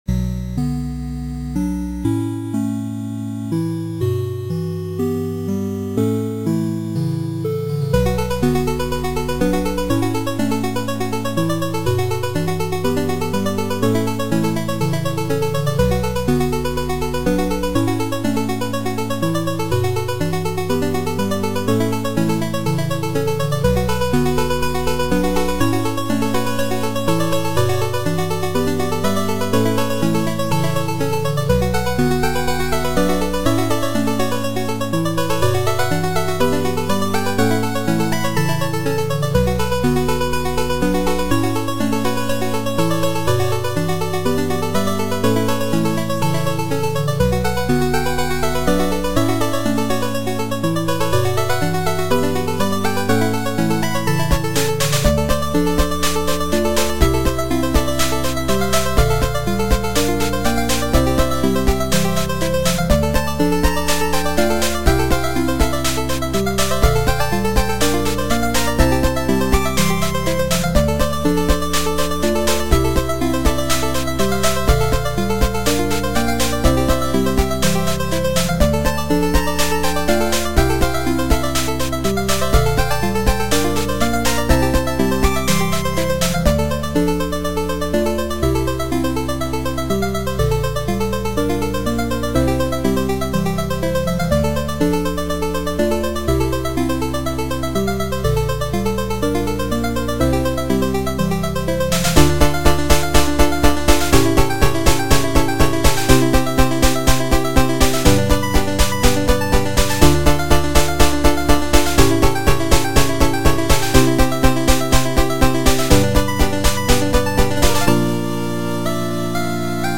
8bit